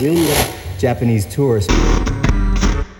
80BPM RAD5-L.wav